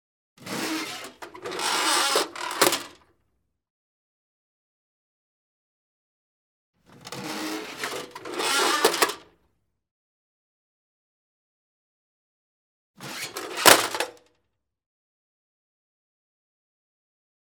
household
Metal Theatre Film Can Open Lid